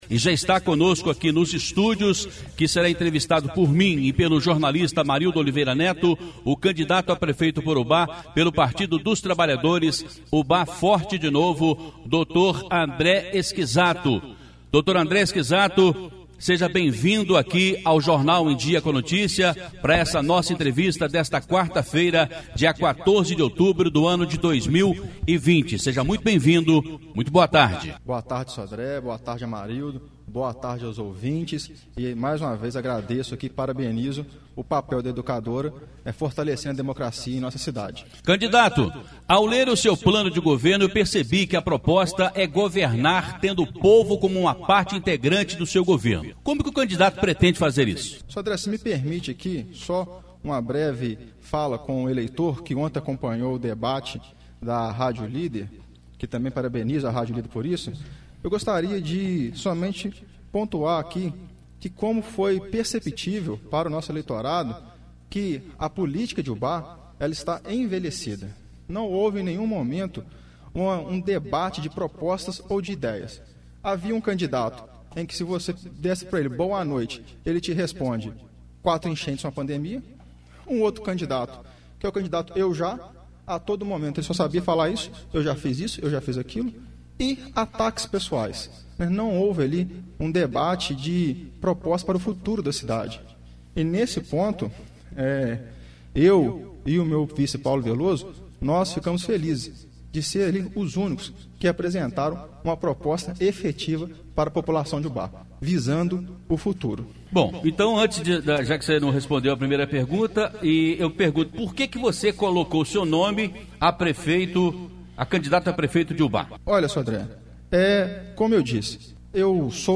Educadora na boca da urna!Entrevista às 12h30.
Entrevista exibida na Rádio Educadora AM/FM Ubá-MG